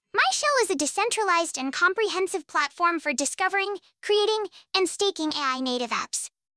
Even with the reference voices provided (in the repository), both v1 and v2 are less accurate than the website when run on my machine.